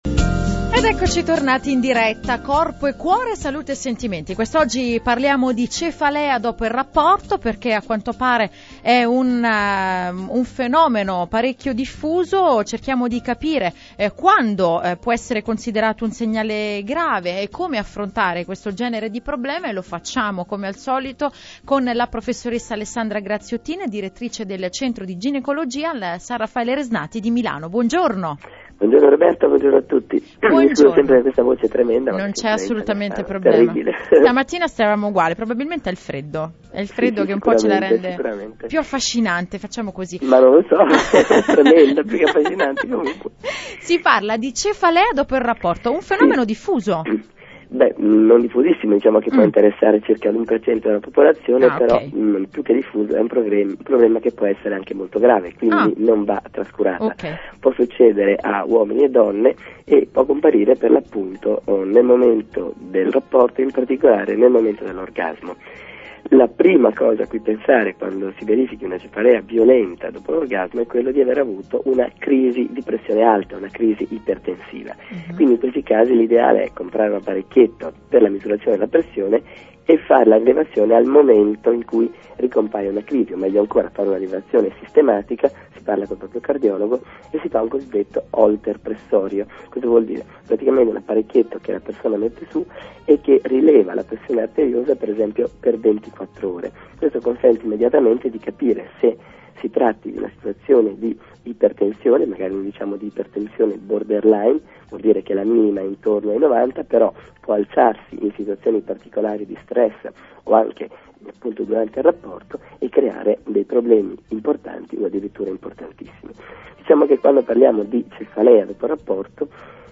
Sintesi dell'intervista e punti chiave